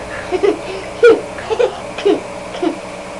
Boy Giggling Sound Effect
Download a high-quality boy giggling sound effect.
boy-giggling.mp3